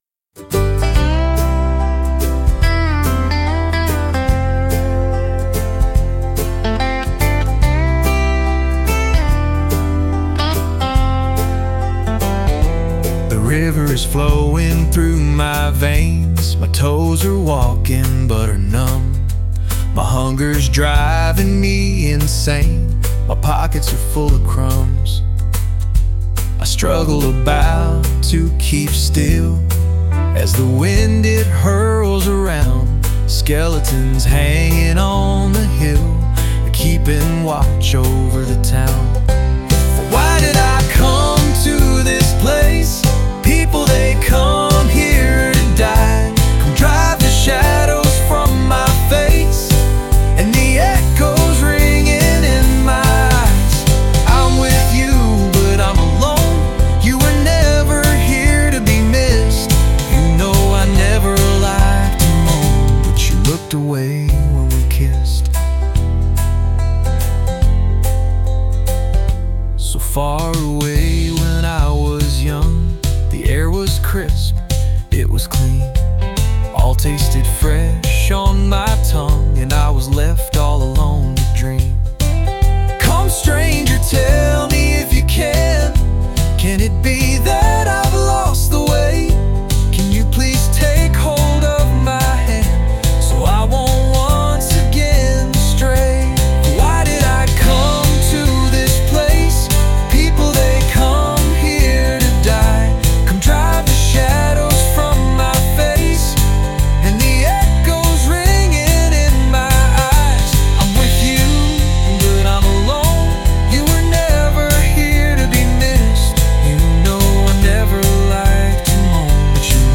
a haunting and emotional ballad